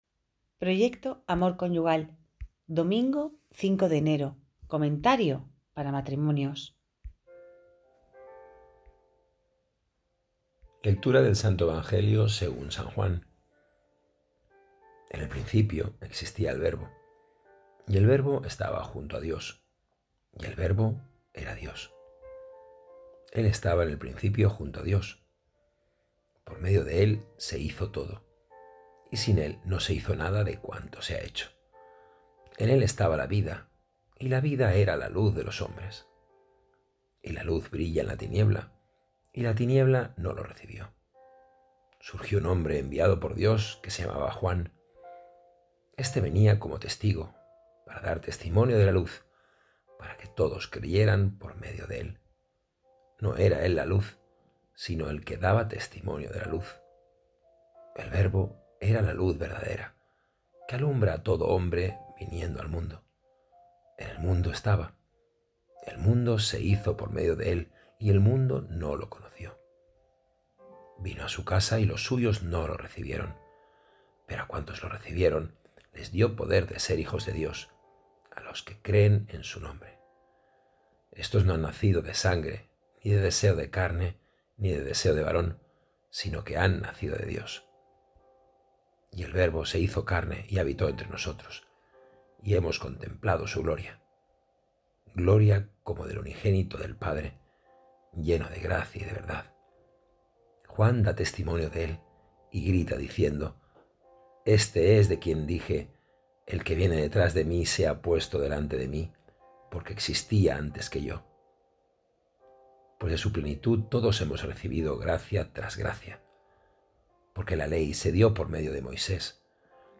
Evangelio del día
Evangelio-5-de-enero-2025.mp3